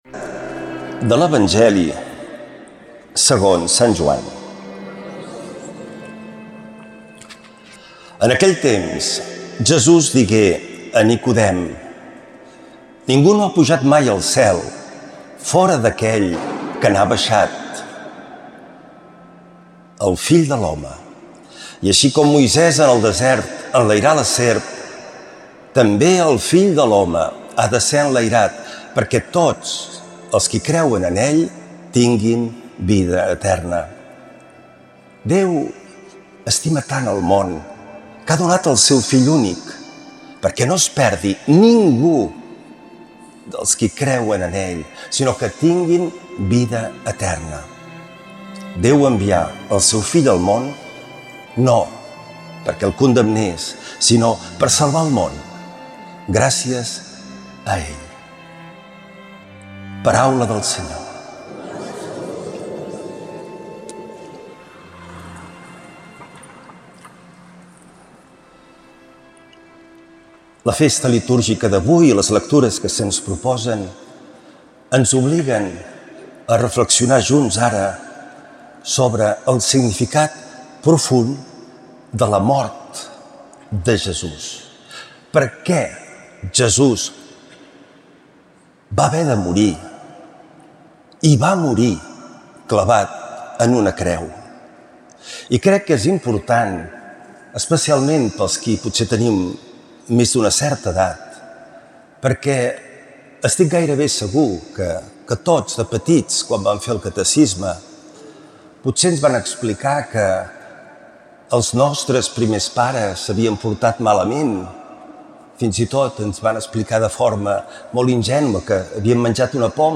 Lectura de l’evangeli segons sant Joan